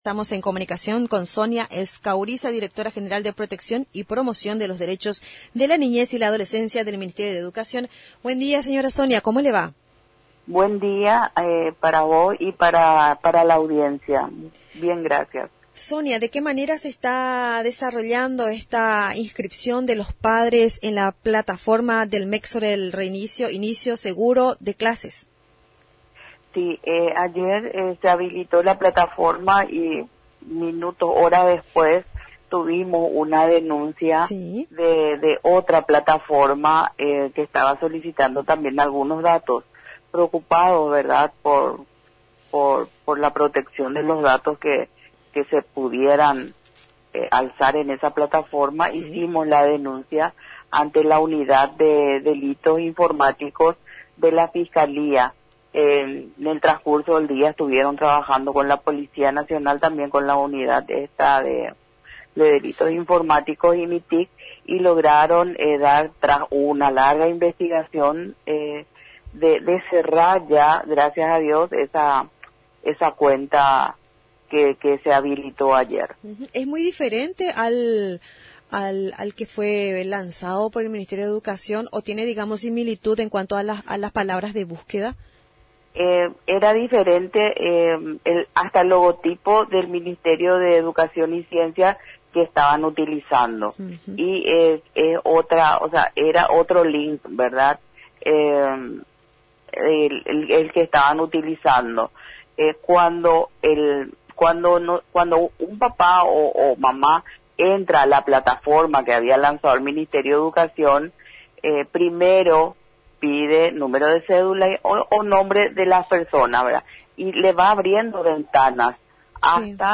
La plataforma en cuestión tenia logotipos diferentes, el programa oficial que el MEC está utilizando, al ingresar el usuario, primero le solicita número de cédula o nombre lo que, a su vez, va desplegando ventanas, hasta donde uno elije el modo virtual o presencial, es ahí, que se completa el formulario resguardando los datos de los menores, mencionó en una entrevista a Radio Nacional del Paraguay, Sonia Escauriza, Directora de Protección de los derechos de la niñez.